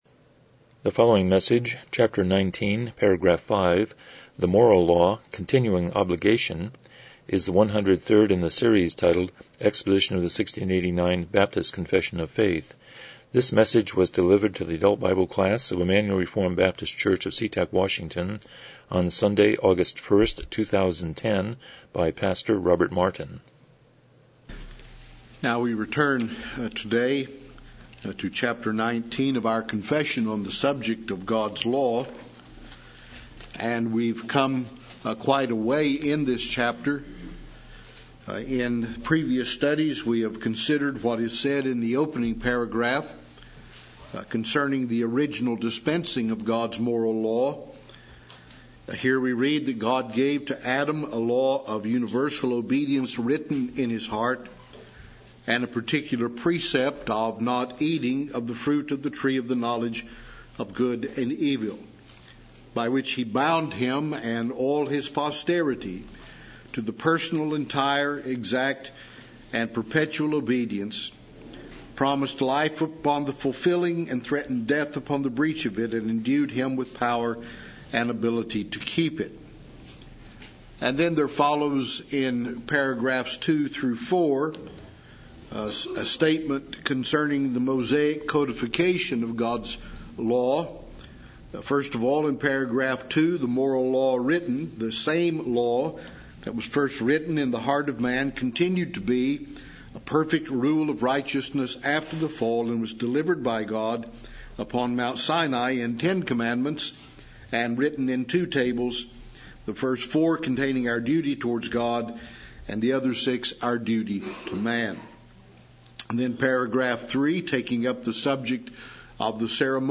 1689 Confession of Faith Service Type: Sunday School « 5 Philippians 1:9-11 The Miracle at Nain